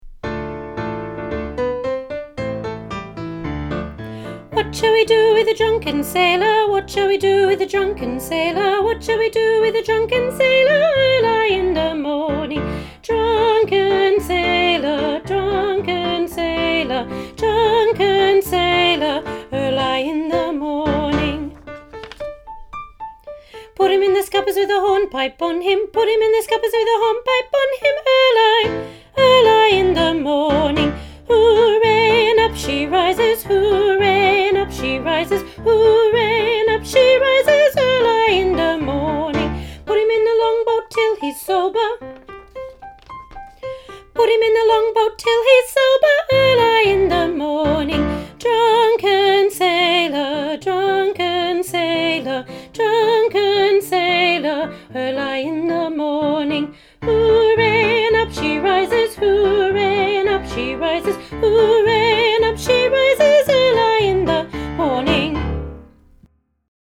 Junior Choir – The Drunken Sailor, Part 2